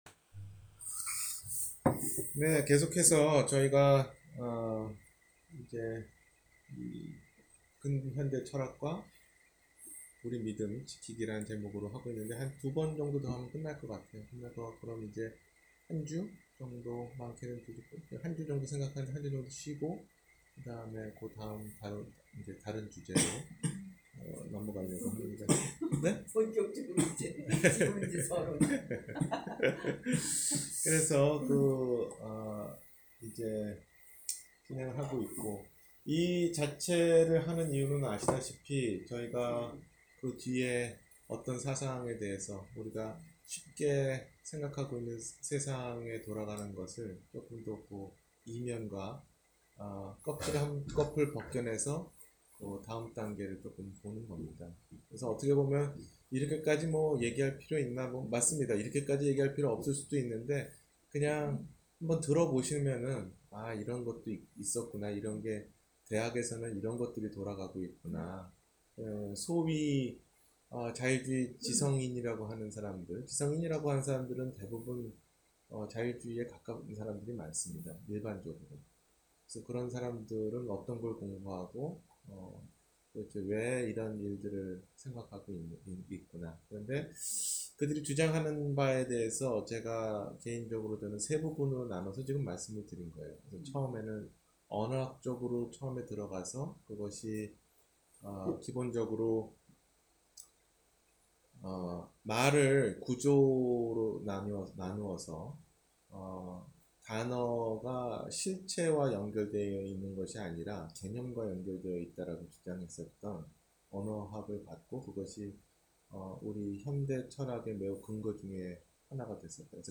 근현대철학과 믿음 지키기 6 – 토요성경공부